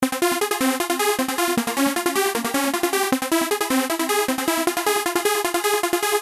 它是一个循环的低音合成器
标签： 155 bpm Electronic Loops Bass Loops 1.04 MB wav Key : Unknown
声道立体声